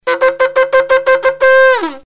During the blowing of the shofar on Rosh Hashana there are three distinct sounds.
teruah.mp3